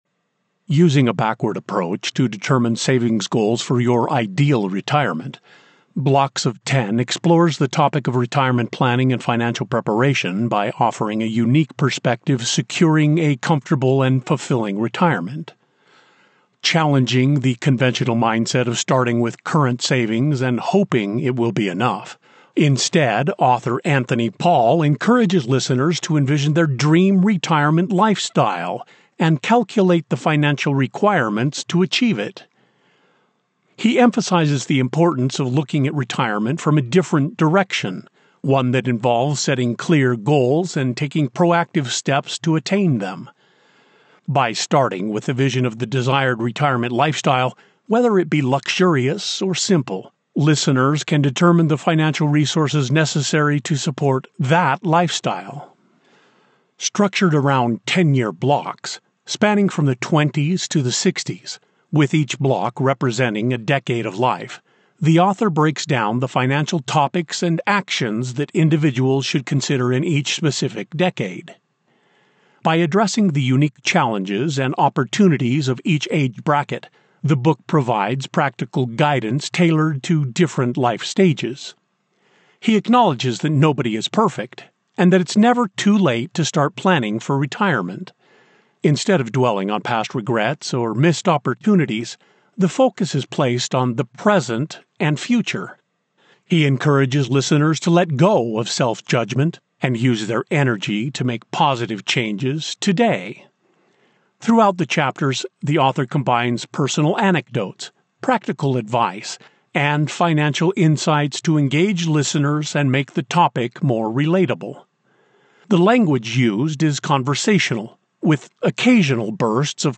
Rich • Robust • Trustworthy
A warm embrace of fatherly wisdom.
Non-Fiction • Finance
General American